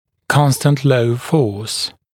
[‘kɔnstənt ləu fɔːs][‘констэнт лоу фо:с]постоянно действующая небольшая сила